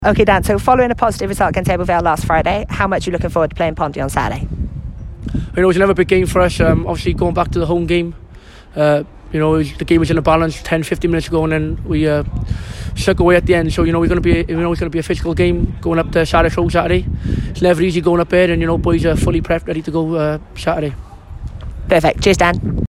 Pre-Match Interview